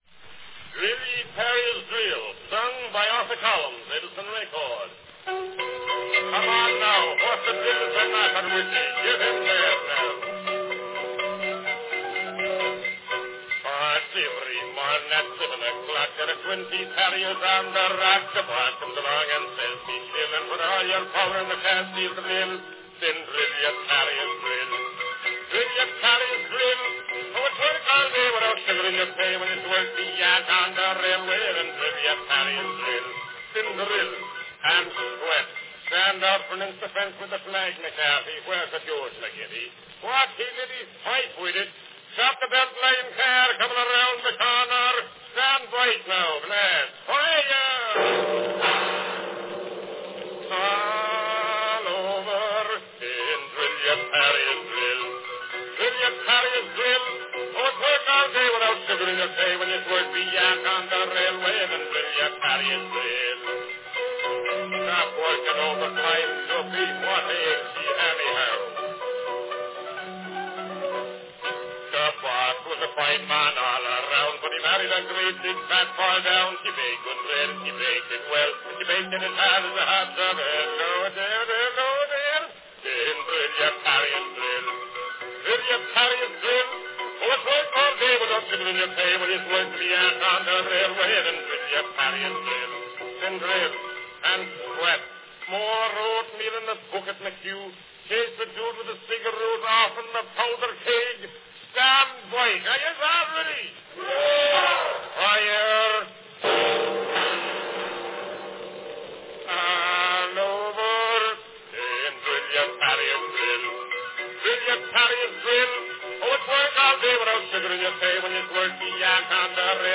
From 1902, the classic descriptive comic song – Drill, Ye Tarriers, Drill – recounting the days of Irish workers drilling and blasting for the railways.
Cylinder # 1020 (5" diameter)
Category Song
Performed by Arthur Collins
Announcement "Drill, Ye Tarriers, Drill, sung by Arthur Collins.  Edison record."
Written in 1888 by Thomas Casey, this popular railroad-themed comic song brings well to the imagination of freewheeling & wild days, and this well-recorded 5-inch diameter "concert" cylinder includes many sound effects lending life to the expression "railroad boom".